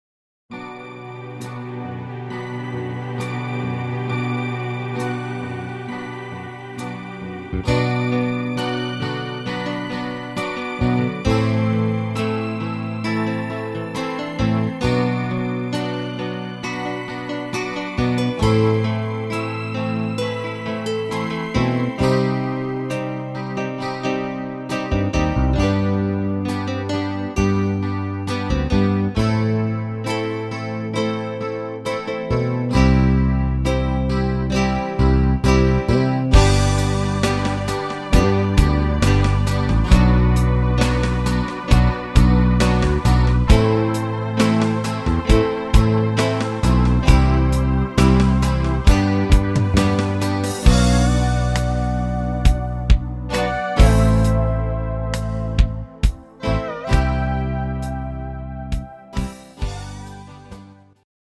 Autria Rock-Pop